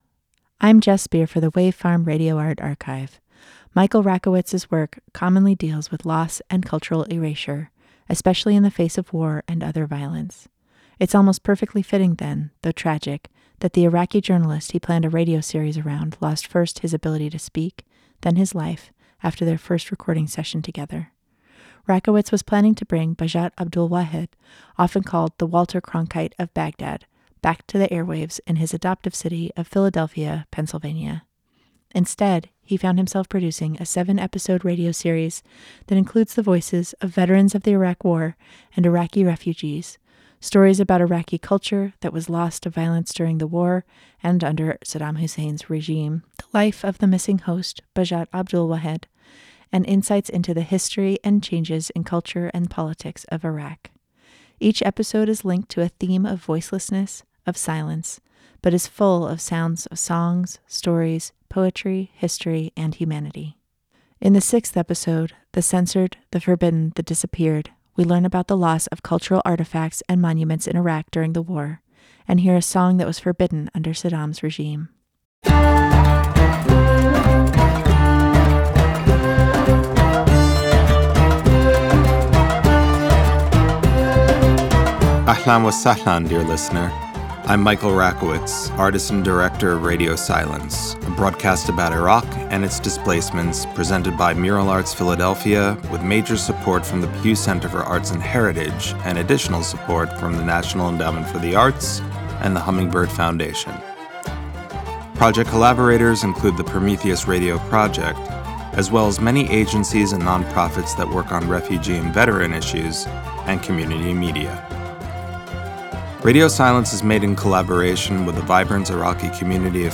Each episode is linked to a theme of voicelessness, of silence, but is full of sounds of songs, stories, poetry, history, and humanity. As with his other artworks, Rakowitz recruits participants to engage in the art, featuring writing by Iraq war veterans, a radio play by an Iraqi artist, Iraqi music, and interviews with historians, journalists, and everyday people.